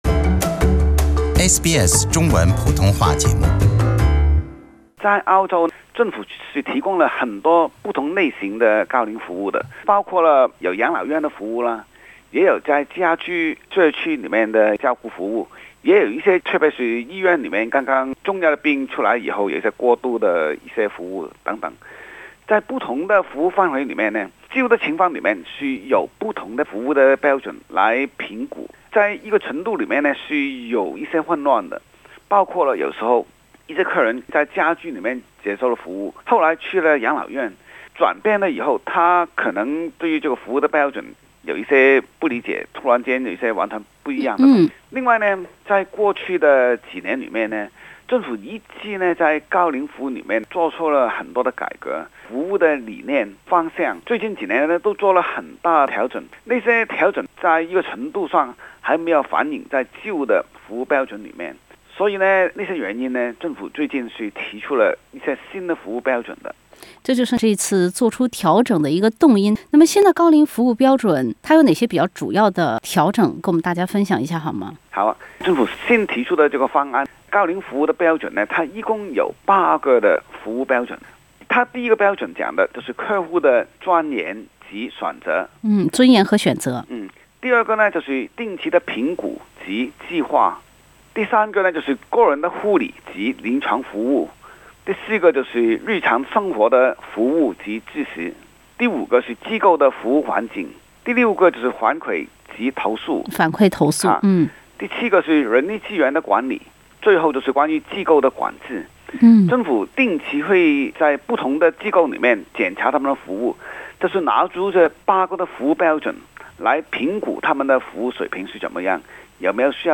The federal government will begin implementing new standards for aged care service in July next year. Interview